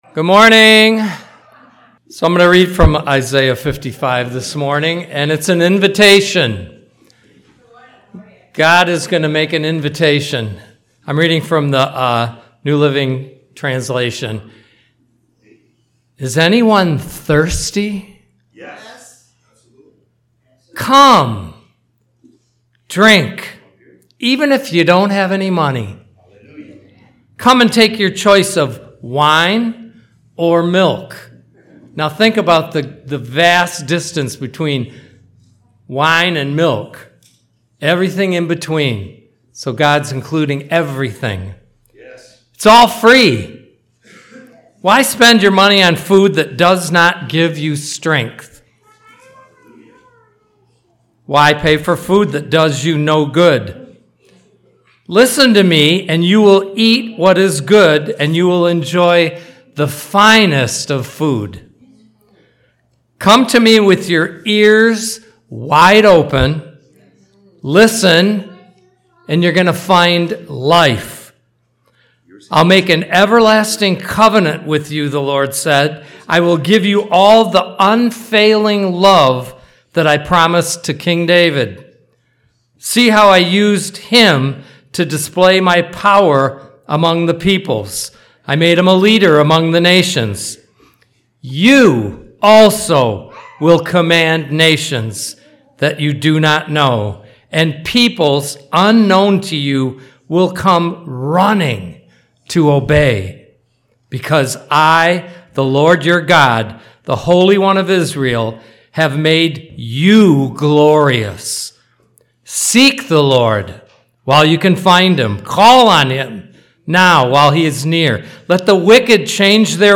Various Service Type: Sunday Service